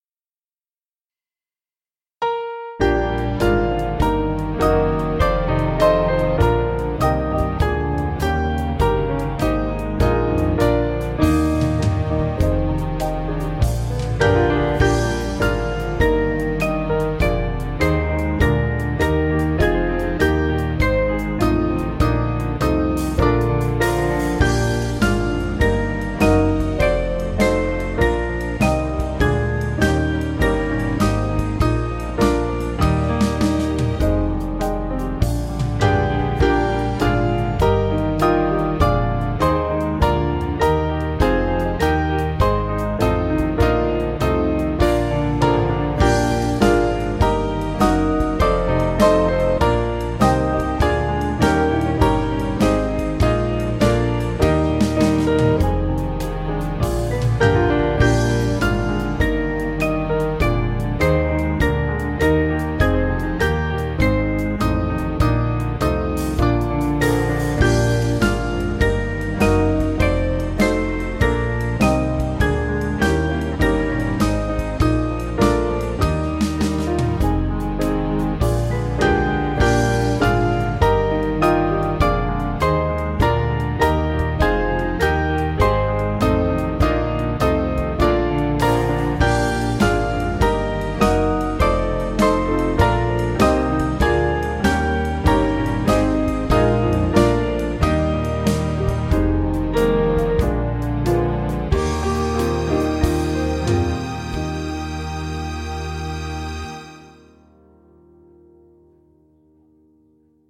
Small Band
(CM)   4/Eb